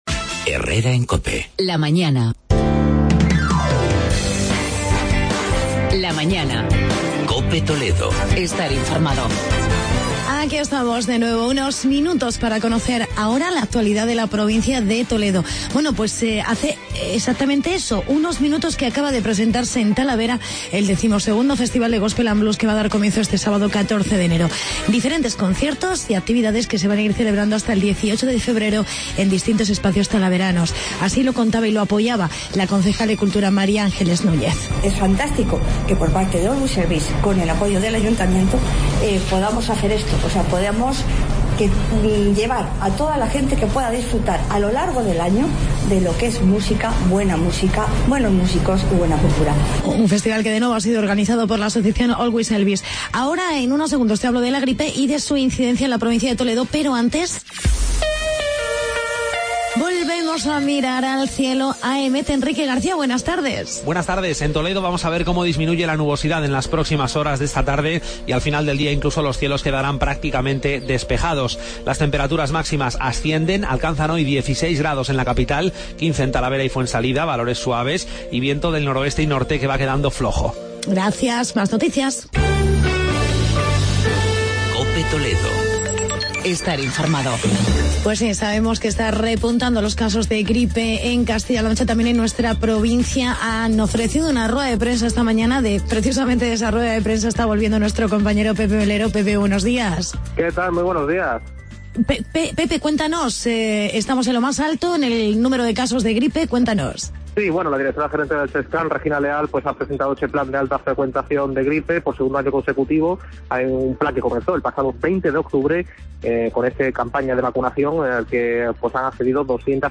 Actualidad y entrevista con el viceportavoz del Ayuntamiento de Talavera, Santiago Serrano.